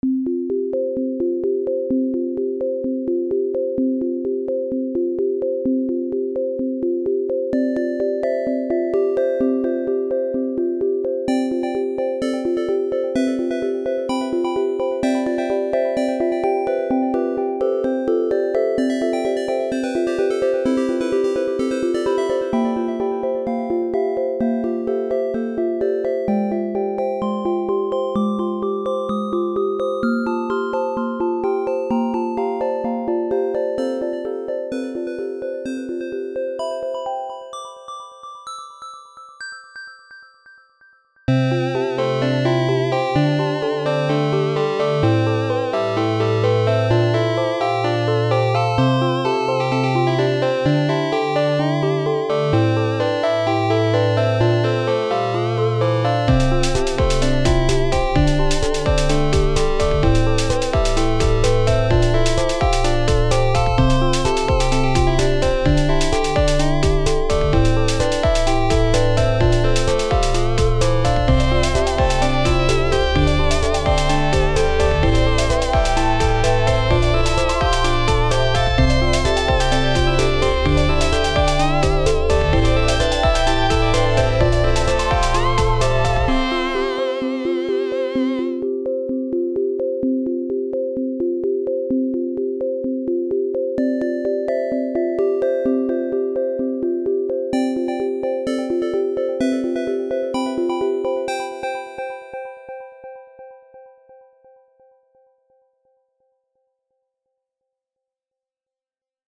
Worked beautifully.
lovely, great tunes and tones.